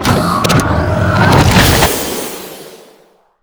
combat drone launch.wav